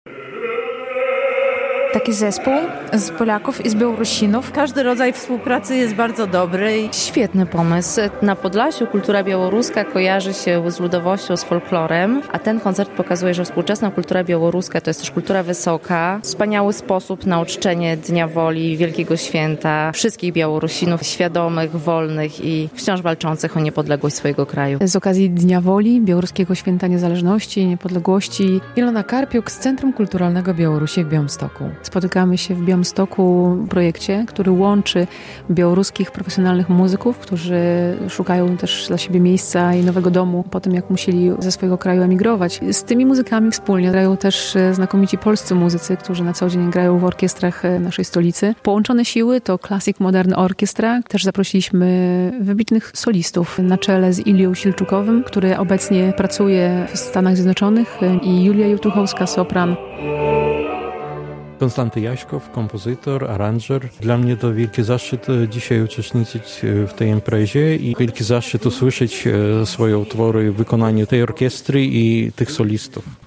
relacja
Uroczystości Dnia Woli obchodzono także w Białymstoku.